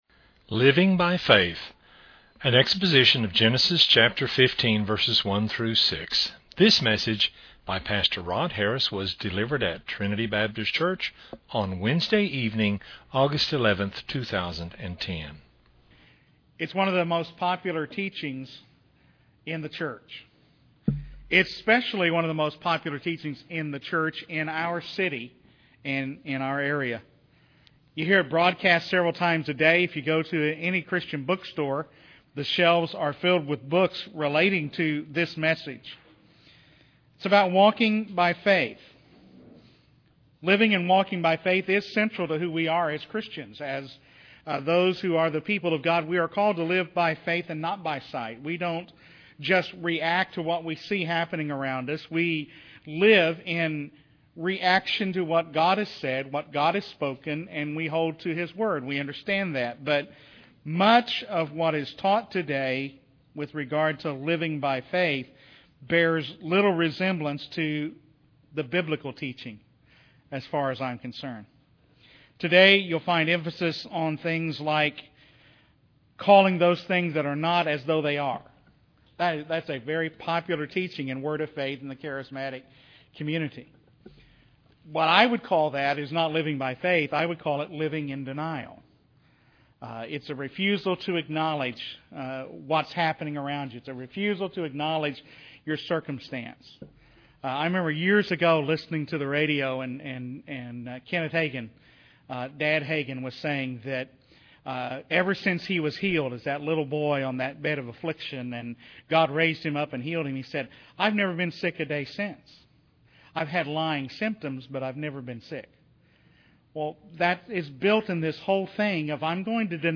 A Wednesday-evening Bible study of Genesis 15:1-6